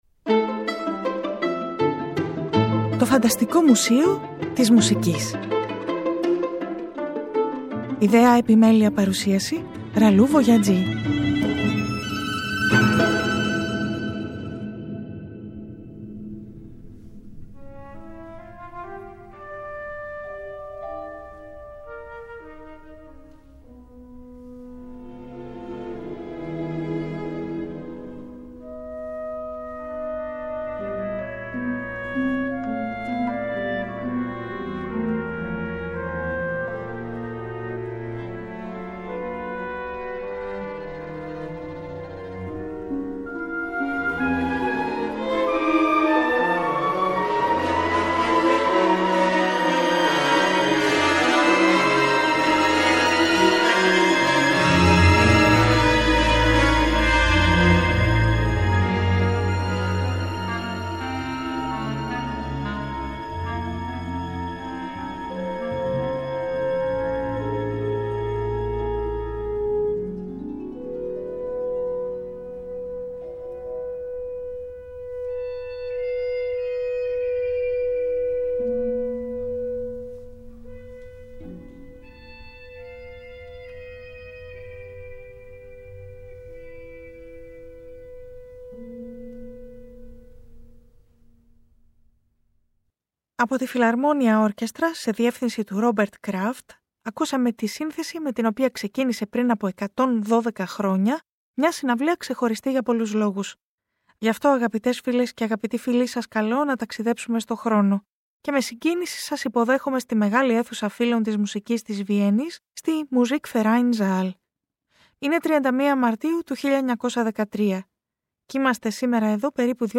Το 2024 Το ΦΑΝΤΑΣΤΙΚΟ ΜΟΥΣΕΙΟ της ΜΟΥΣΙΚΗΣ (Τhe FANTASTIC MUSEUM of MUSIC) είναι μια ραδιοφωνική εκπομπή , ένας «τόπος» φαντασίας στην πραγματικότητα .